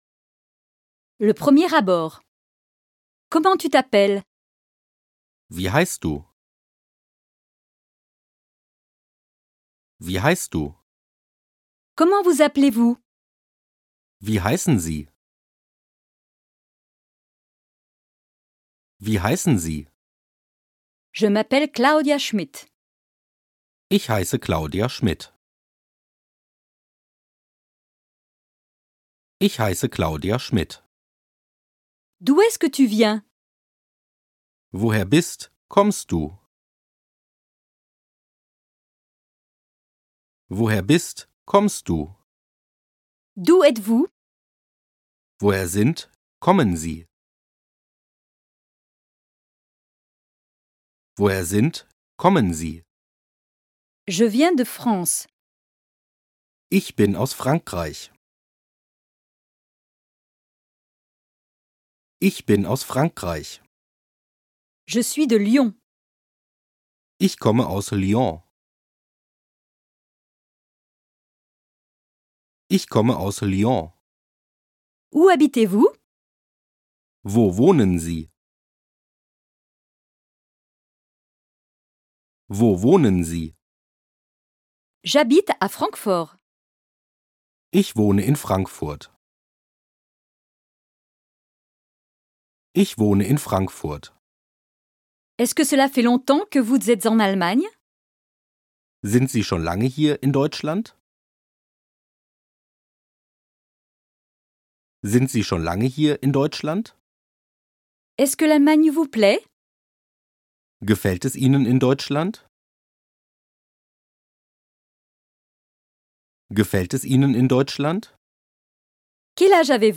Extrait gratuit Le Coach de prononciation Kauderwelsch Allemand